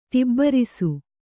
♪ ṭibbarisu